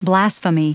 Prononciation audio / Fichier audio de BLASPHEMY en anglais
Prononciation du mot : blasphemy